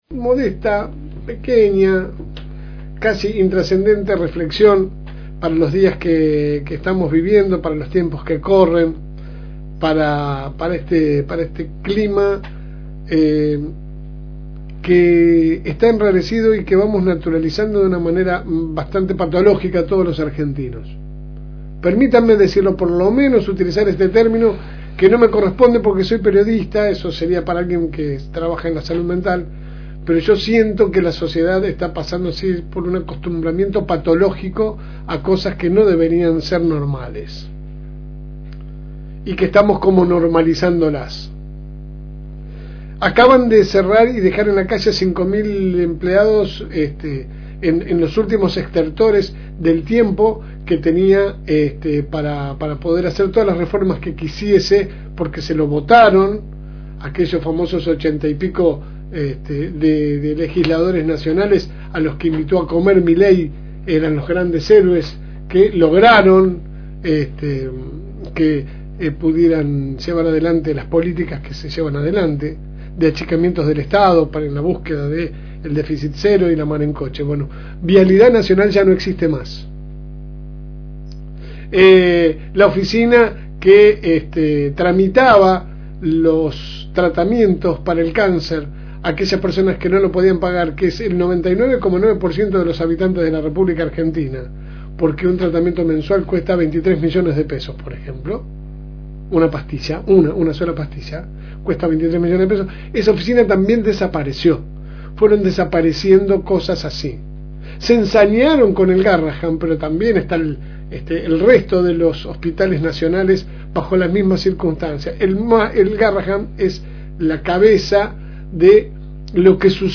AUDIO – Pequeña reflexión – FM Reencuentro